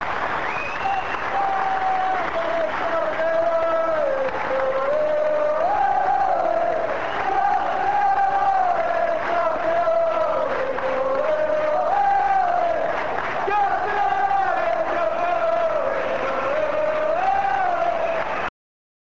This page contains, for the moment, chants from the last game of the season at Easter Road on 8th May 1999, along with Alex McLeish's speech after the game.